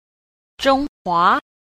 10. 中華 – zhōnghuá – Trung Hoa